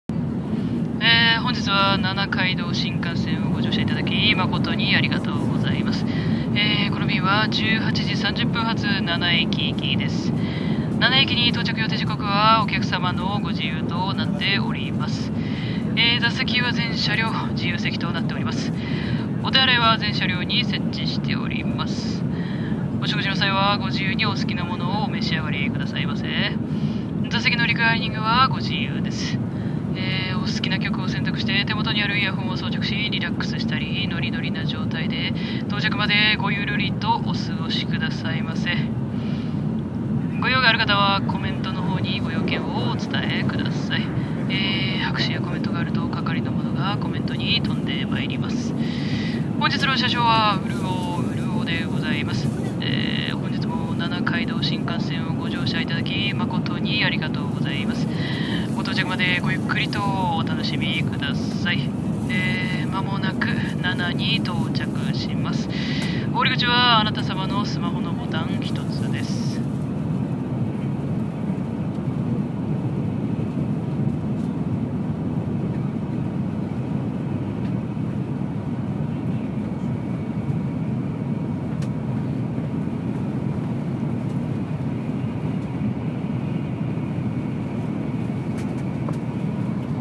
声劇台本 ｢nana街道新幹線アナウンス｣